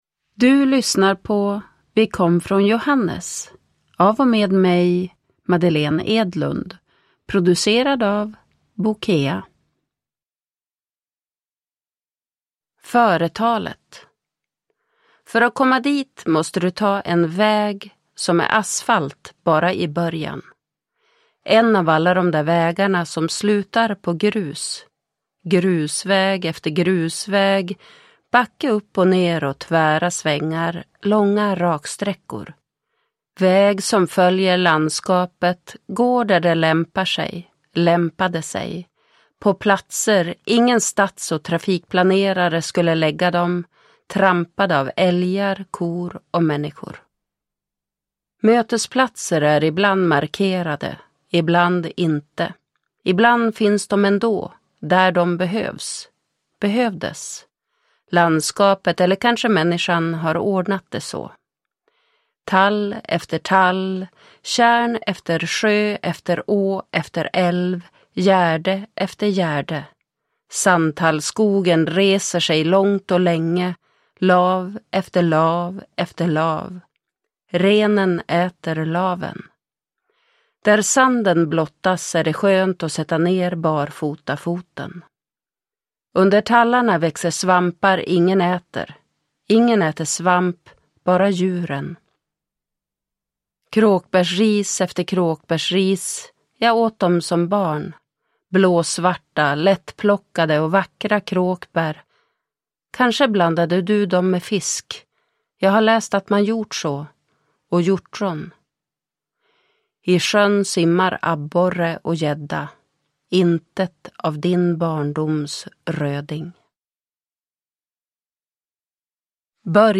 Lyrik
Ljudbok